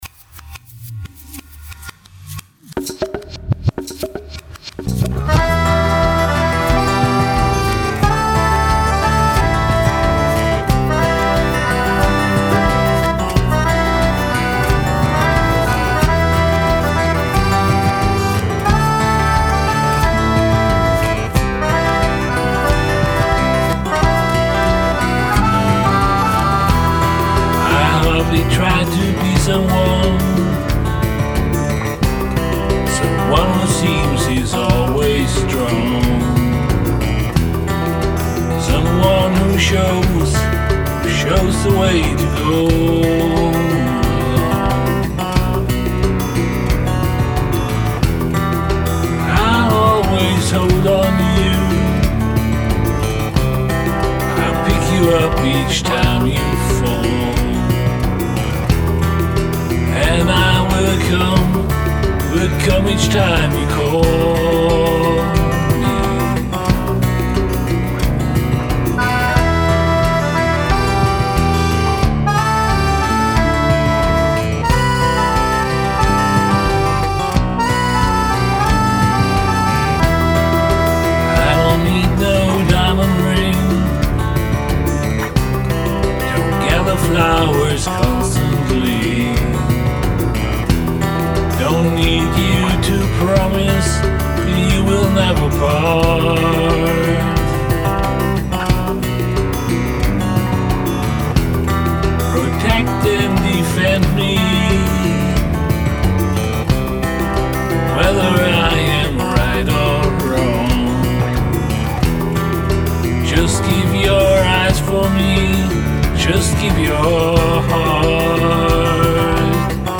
Gitarre
Keyboards
Bass
Vocals